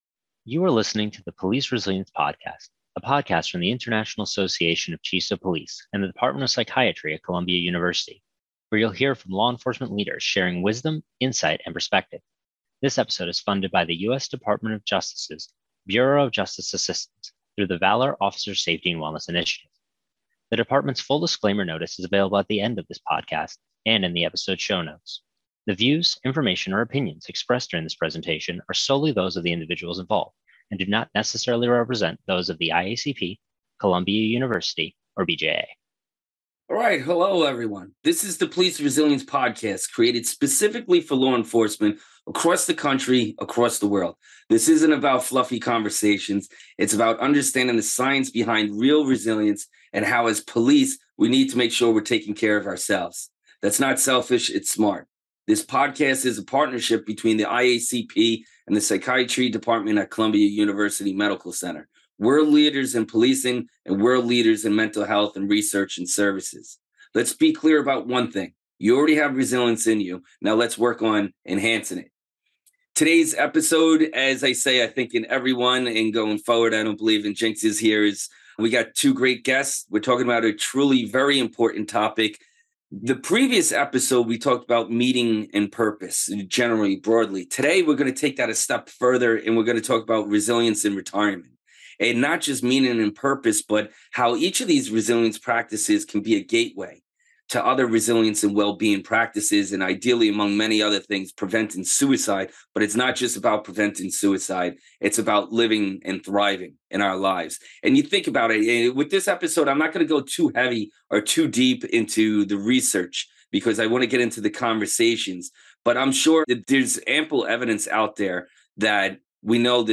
The International Association of Chiefs of Police (IACP) podcast series will feature interviews with special guests and discussions on the leading issues of the day.